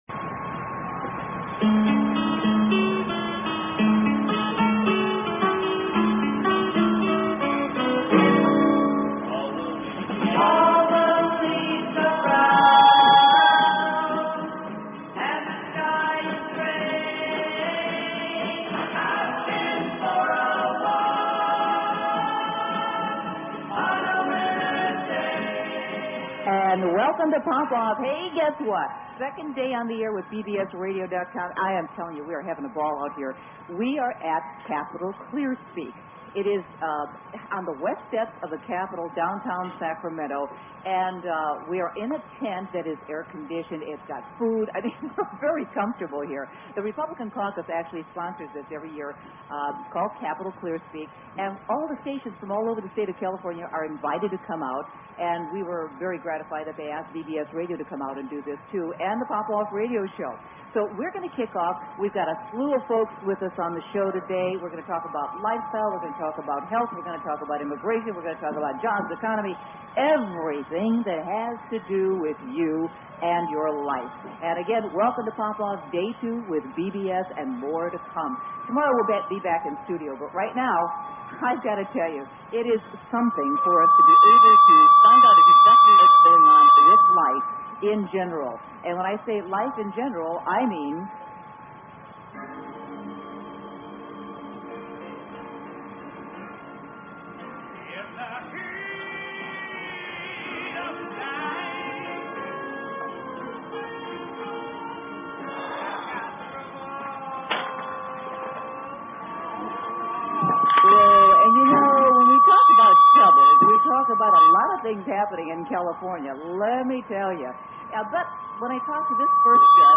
Talk Show Episode, Audio Podcast, Poppoff and Courtesy of BBS Radio on , show guests , about , categorized as
A fast-paced two hour Magazine-style Show dedicated to keeping you on the cutting edge of today's hot button issues.